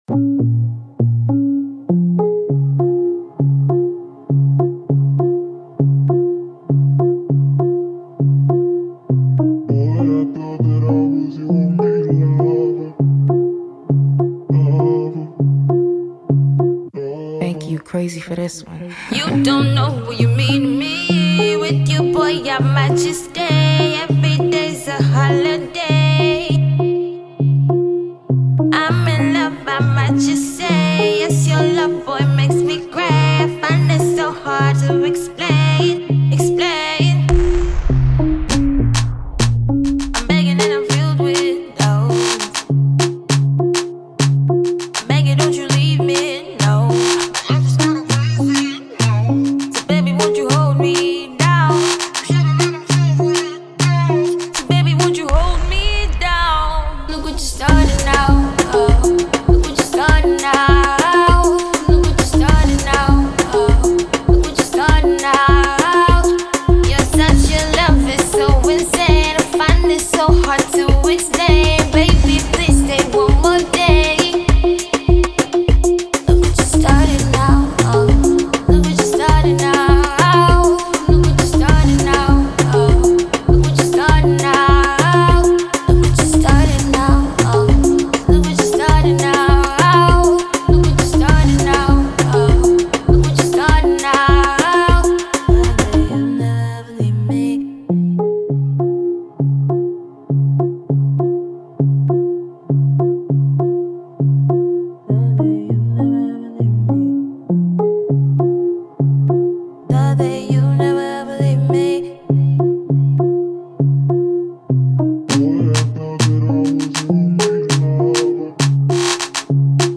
EDM (Electronic Dance Music) styled tune